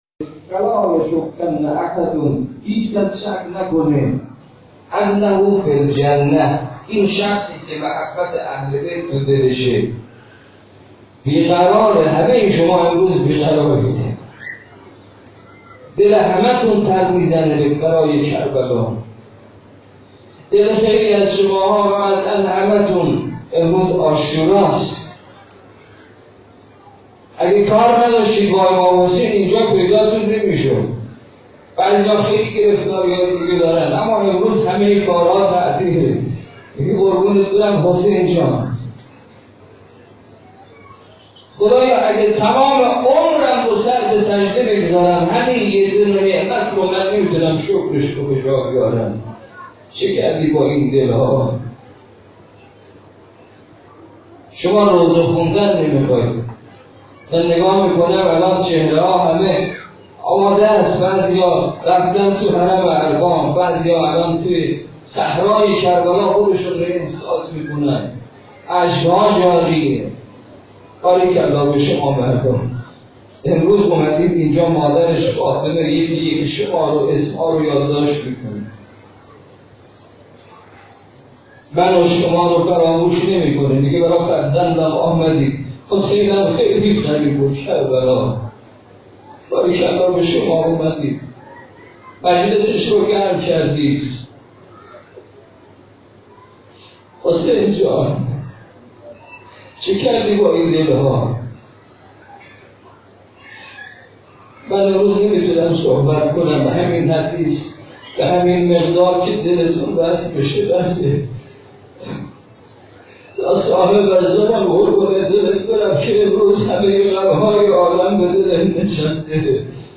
خیمه گاه - مصباح ، رسانه مجازی هیئات مذهبی روستای خانکوک - مقتل خوانی ظهر عاشورا